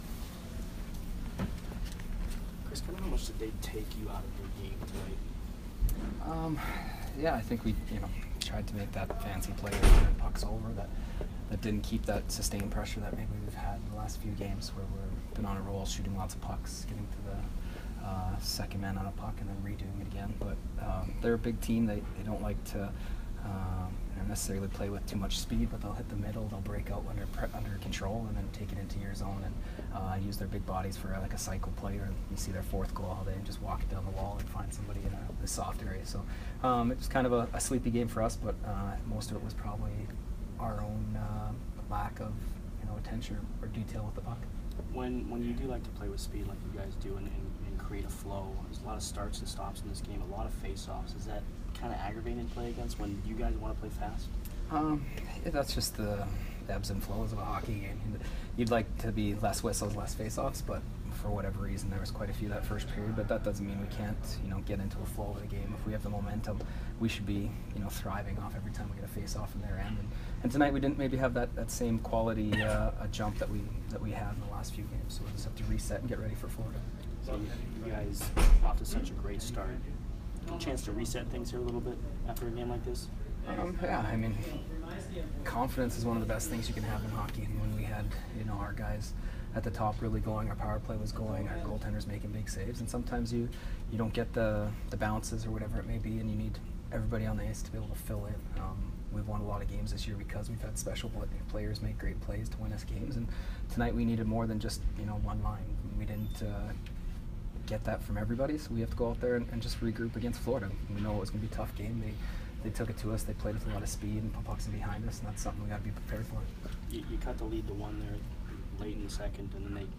Chris Kunitz Post-Game 10/28